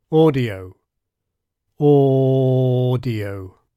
Here I am saying BrE audio, normally then with a prolonged first vowel to demonstrate how unchanging it is:
audio_thought_slow.mp3